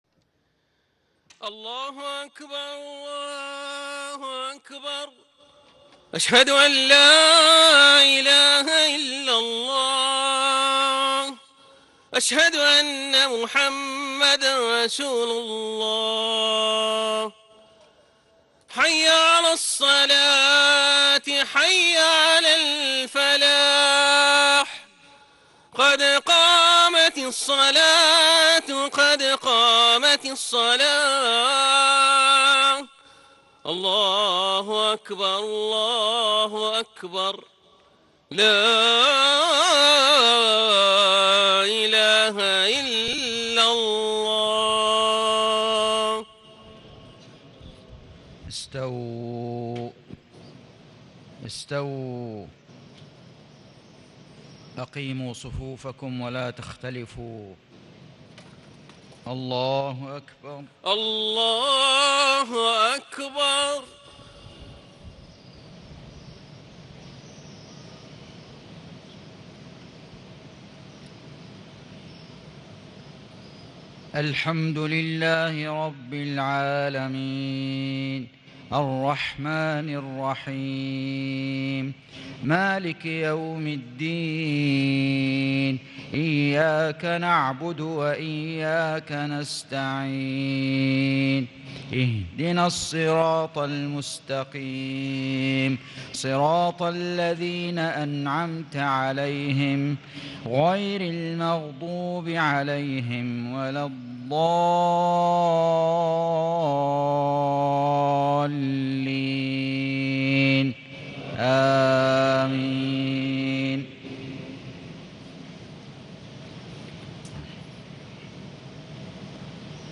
صلاة المغرب 1 ذو الحجة 1437هـ سورتي التين و قريش > 1437 🕋 > الفروض - تلاوات الحرمين